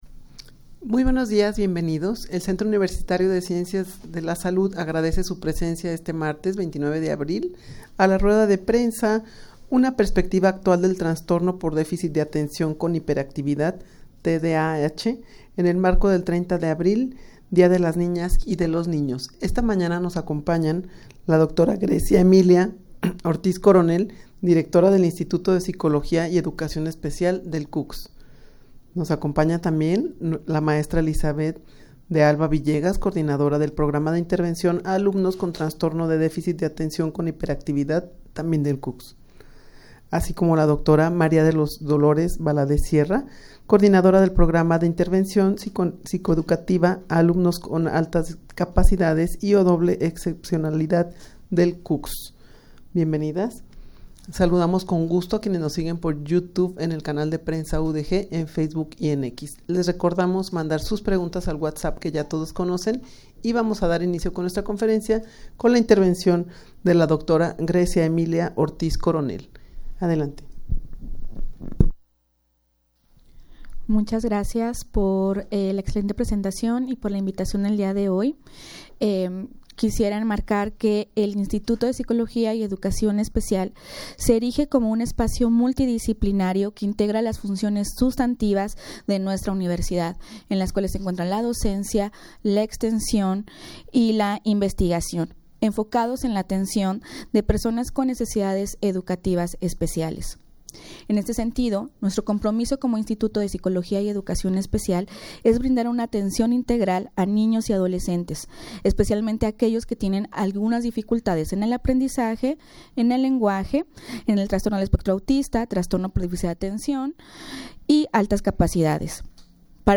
Audio de la Rueda de Prensa
rueda-de-prensa-una-perspectiva-actual-del-trastorno-por-deficit-de-atencion-con-hiperactividad-tdah.mp3